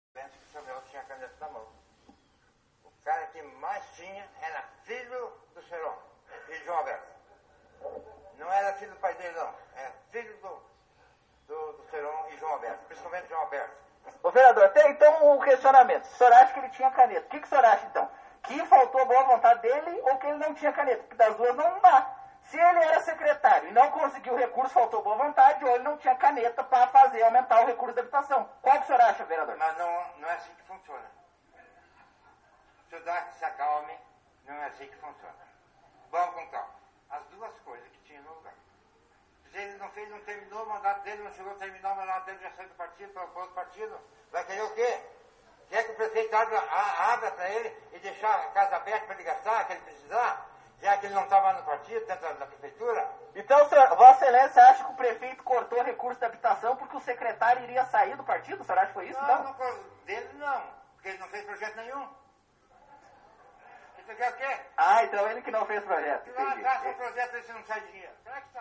Este diálogo entre os vereadores João Chagas (PSC) e Jair Junior (PODE) aconteceu durante a sessão da Câmara de terça-feira.